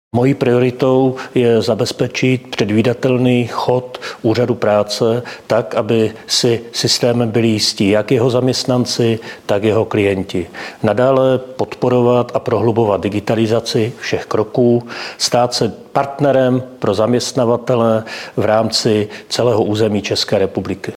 Komentář generálního ředitele ÚP ČR Romana Chlopčíka